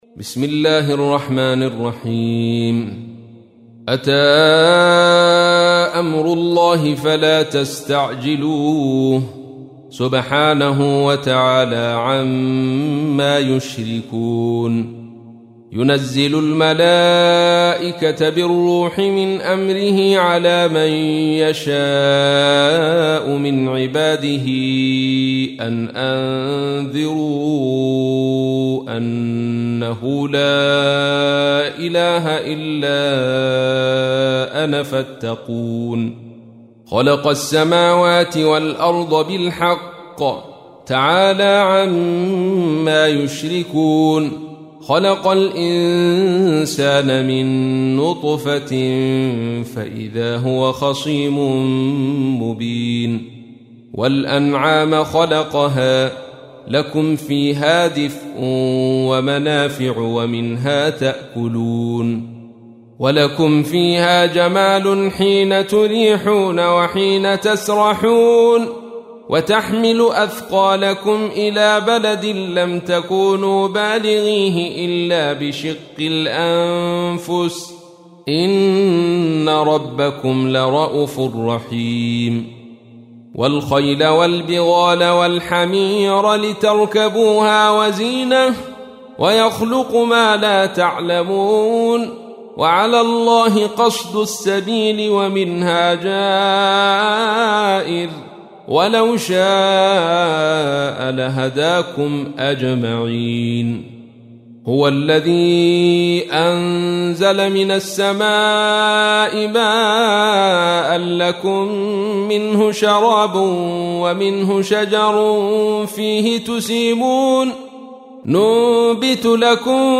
تحميل : 16. سورة النحل / القارئ عبد الرشيد صوفي / القرآن الكريم / موقع يا حسين